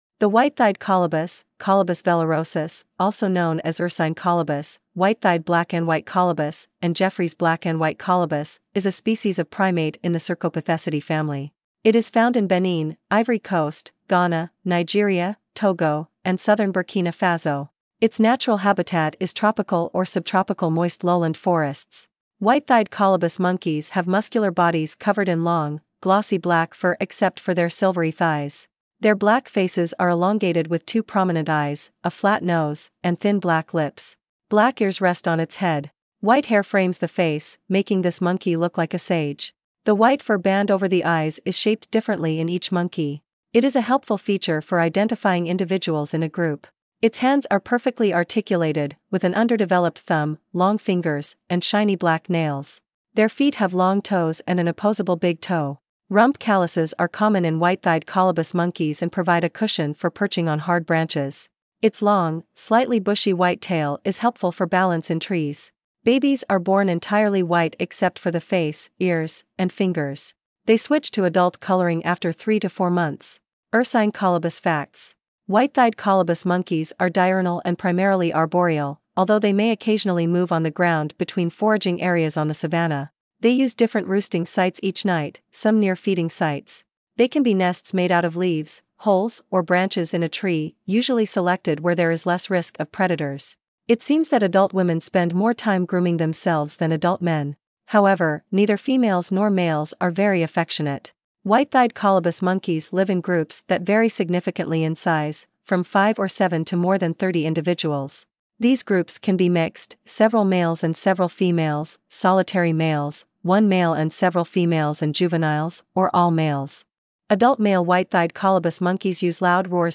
Ursine Colobus
• Adult male white-thighed colobus monkeys use loud roars to call their territory and location. The roar can be multiple times and in concert with other groups, especially in the mornings.
ursine-colobus.mp3